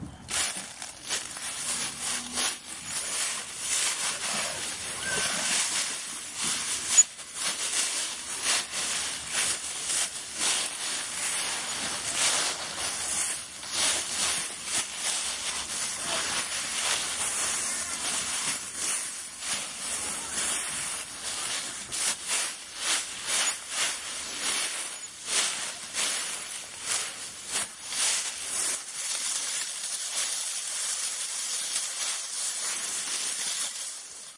窸窸窣窣的叶子
描述：一个MP3版本的原始音频记录的嗖嗖声和沙沙作响的一堆死叶从我的鞋子在操场上的树上落下，然后将麦克风移动到堆中。使用尼康Coolpix相机的麦克风录制。
Tag: 场记录 搅拌 搅动 噼啪 秋天 嗖嗖嗖 裂纹 洗牌 沙沙 沙沙 随机播放 噪音 冲击